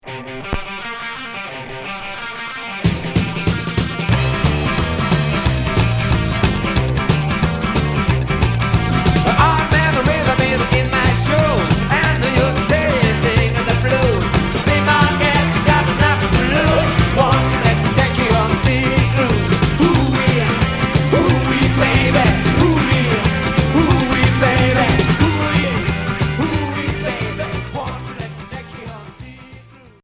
Rockabilly Unique 45t